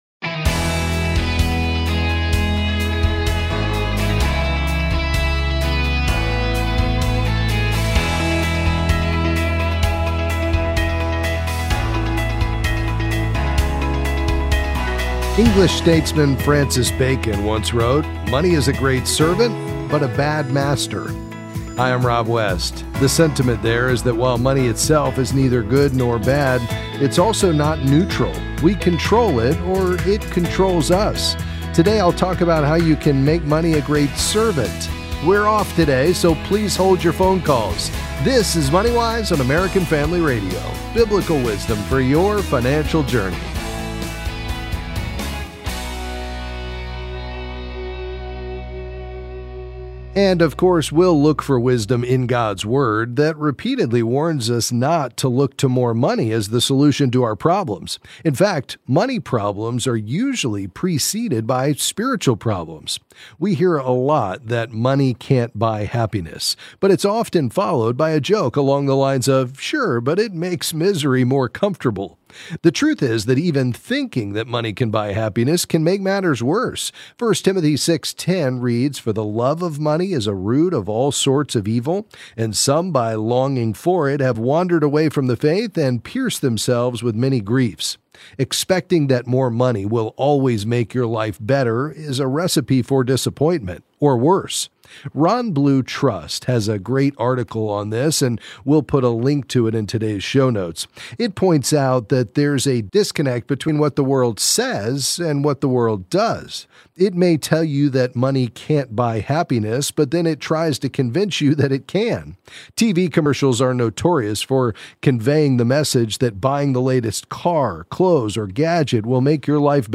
Then he'll answer your calls on a variety of financial topics.